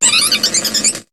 Cri de Galvaran dans Pokémon HOME.